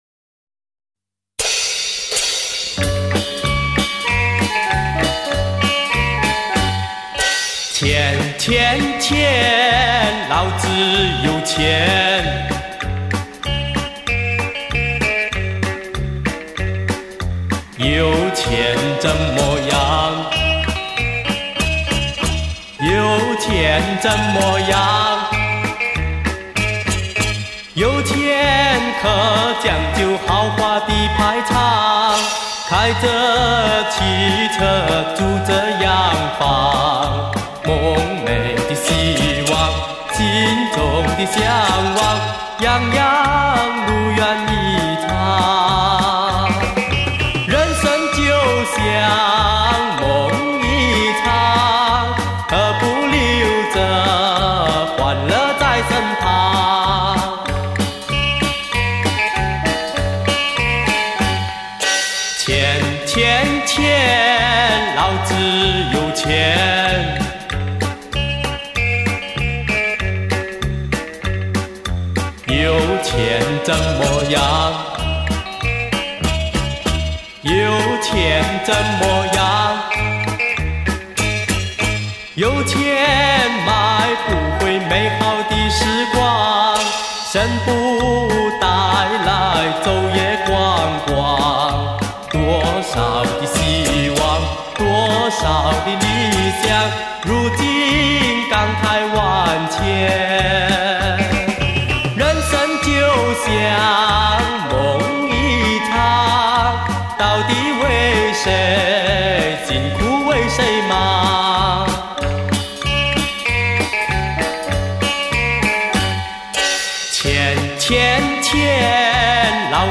版本音质都一流